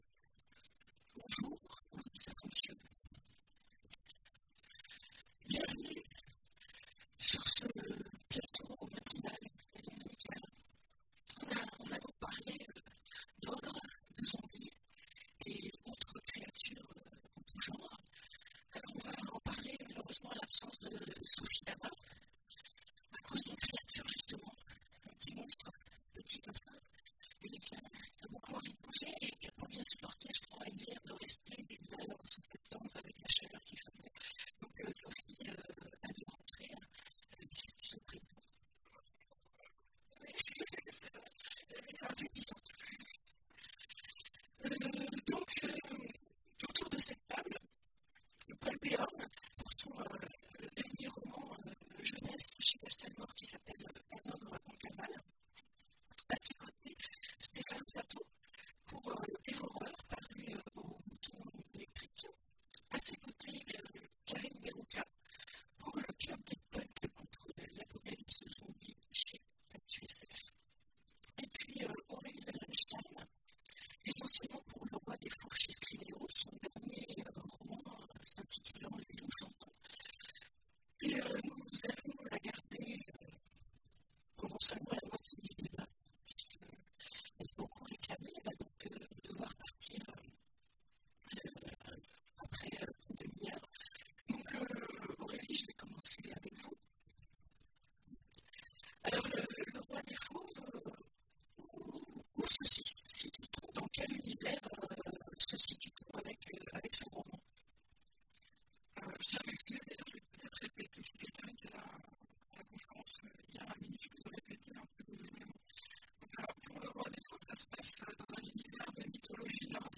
Note : des coupures d'électricité ont perturbé l'enregistrement de la conférence.